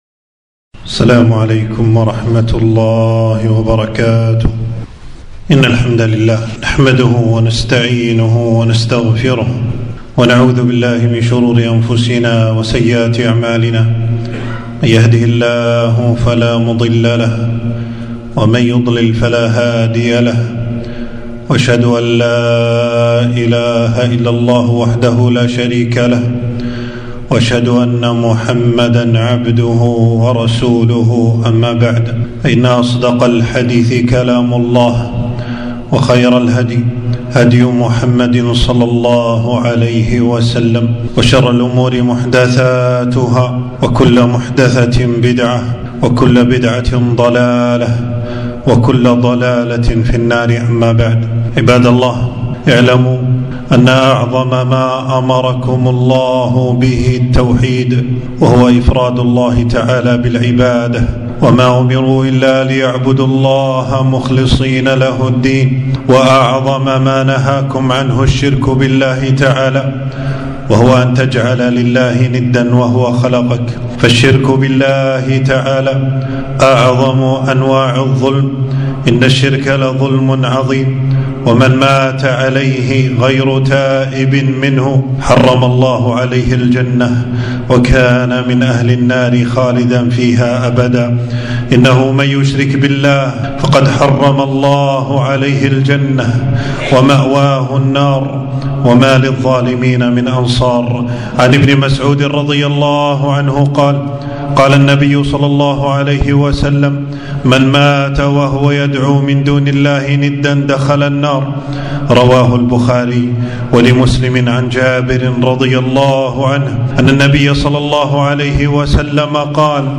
خطبة - التوحيد أعظم الأوامر والشرك أظلم الظلم